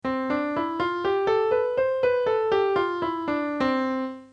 CMajor.mp3